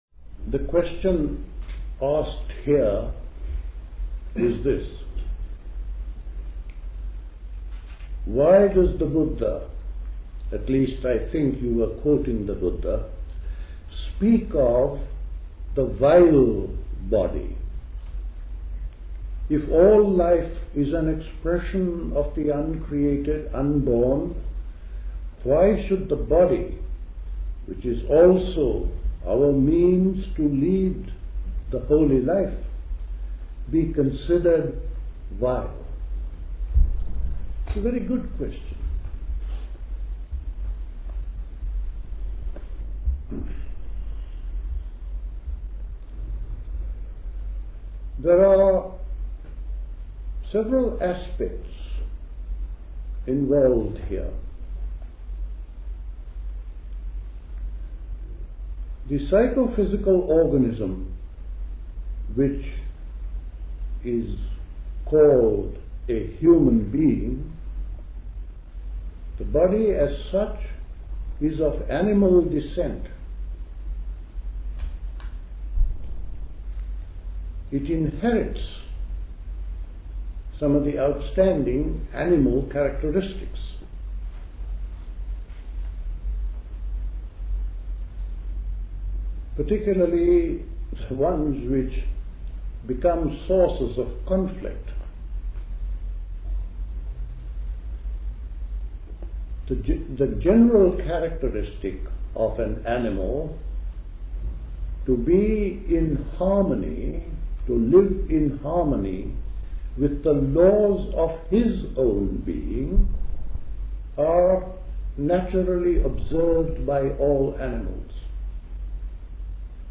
A talk
at High Leigh Conference Centre, Hoddesdon, Hertfordshire on 3rd September 1979